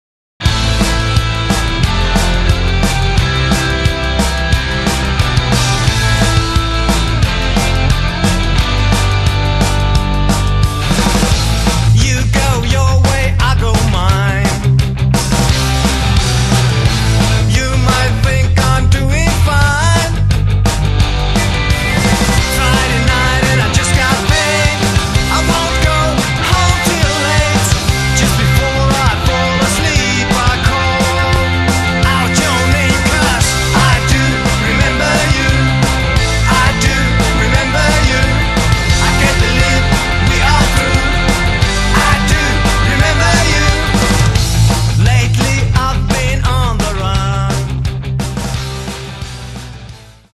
(Compressed/Mono 345kb)   Download!